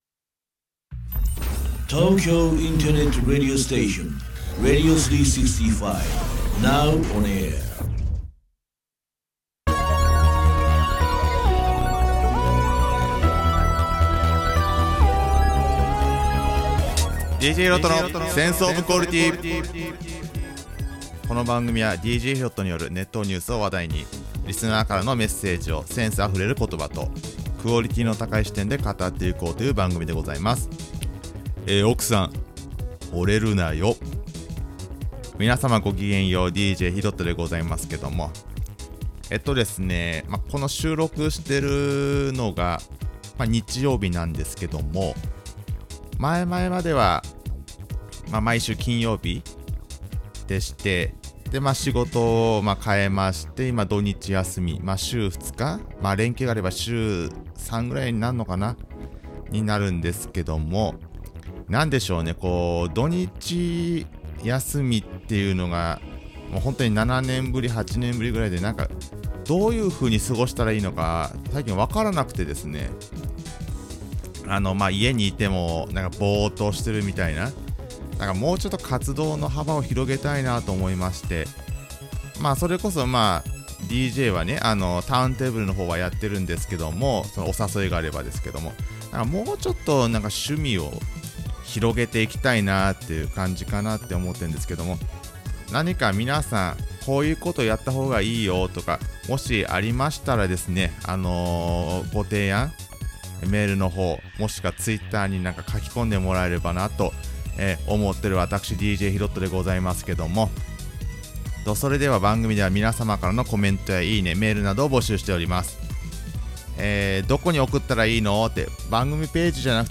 頭がおかしい感じで収録しました！